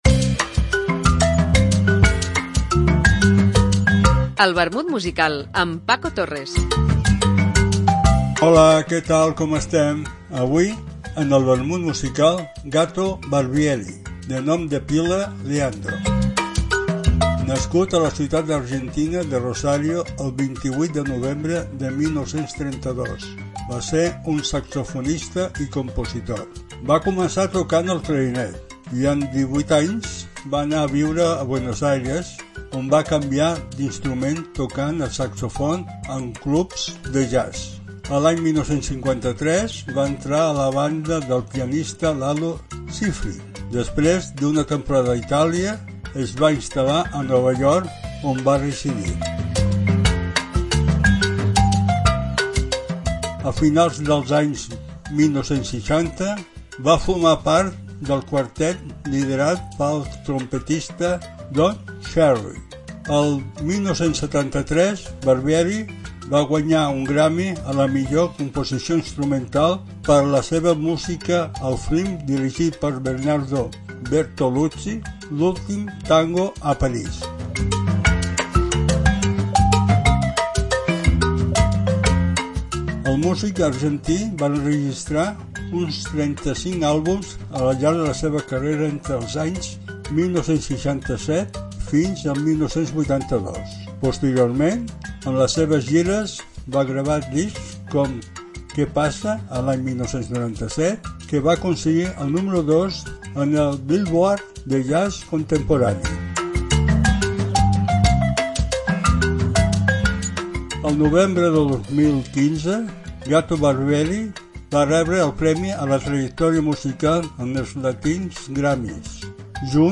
Una apunts biogràfics acompanyats per una cançó